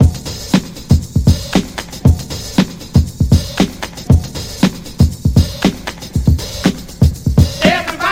• 118 Bpm HQ Breakbeat E Key.wav
Free drum loop - kick tuned to the E note. Loudest frequency: 1187Hz
118-bpm-hq-breakbeat-e-key-cO6.wav